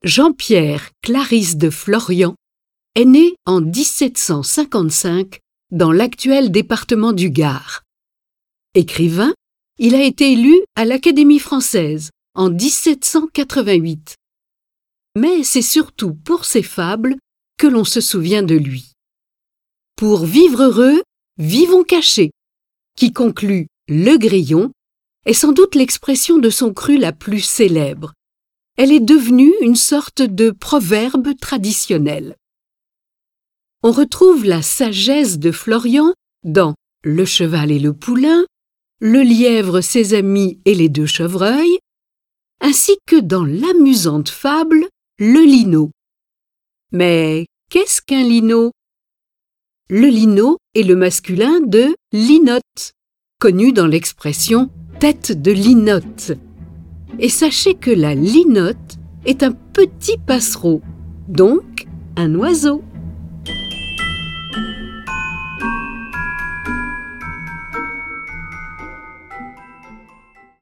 Toute sa vie, Florian aura rédigé des textes optimistes et en même temps plus futés qu'il n'y paraît. Interprétées par 7 voix et accompagnées de plusieurs morceaux de musique classique, ces 18 fables complétées de 5 commentaires enchanteront nos oreilles.
Les poèmes sont illustrés avec les musiques de Beethoven, Charpentier, Debussy, Delibes, Grieg, Haydn, Mozart, Rossini, Schubert, Tchaikovski et Telemann.